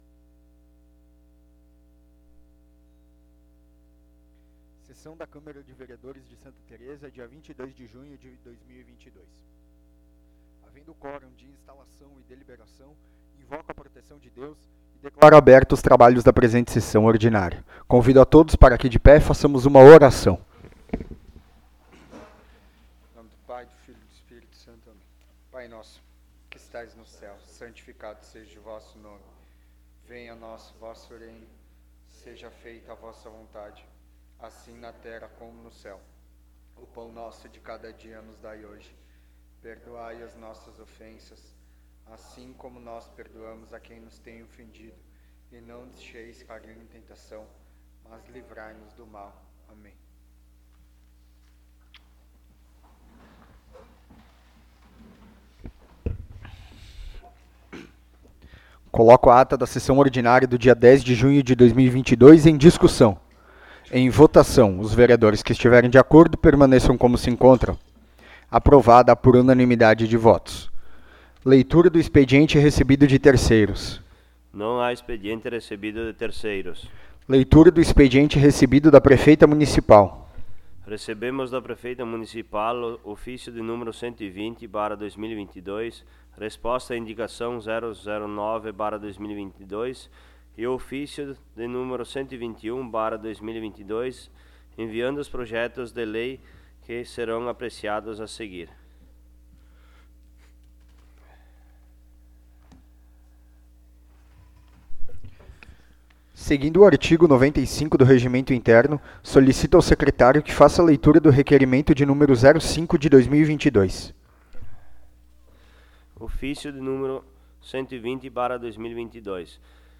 10ª Sessão Ordinária de 2022
Áudio da Sessão